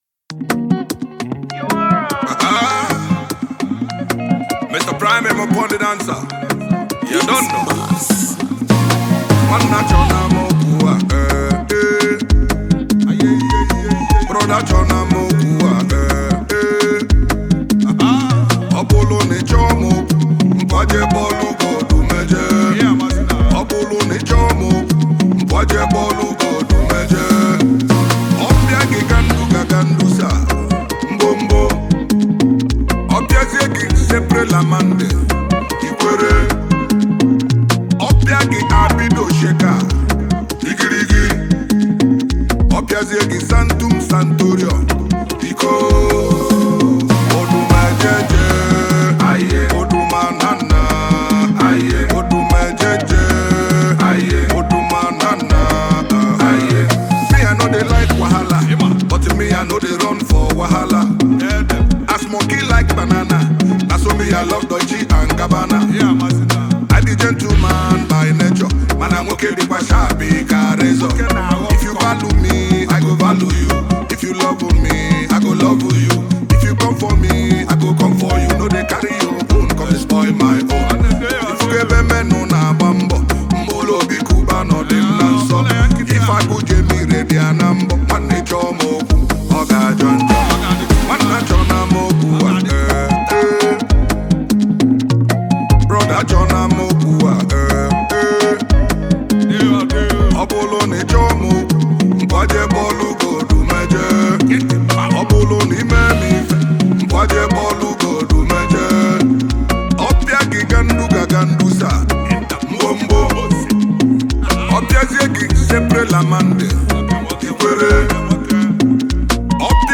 entertaining Highlife music